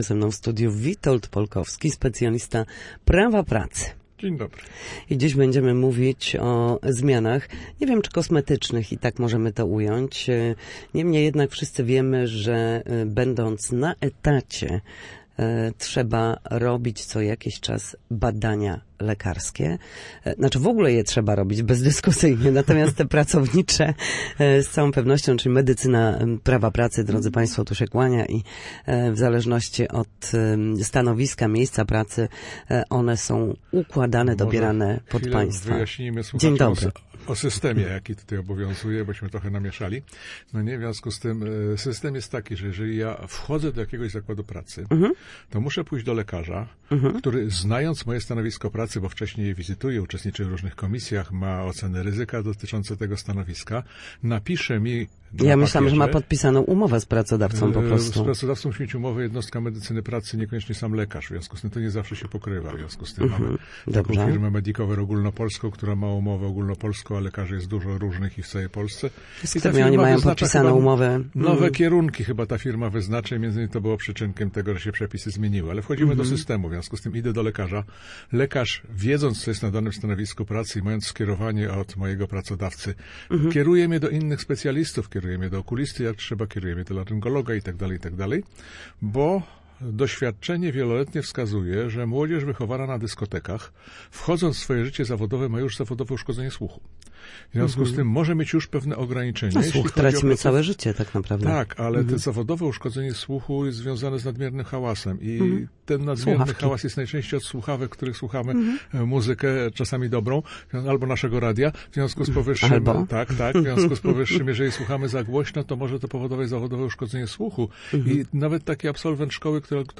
Medycyna prawa pracy oraz zmiany w przepisach dotyczących zwolnień lekarskich to temat, który budzi ogromne emocje zarówno u pracodawców, jak i pracowników. Między innymi na ten temat rozmawialiśmy w Studiu Słupsk.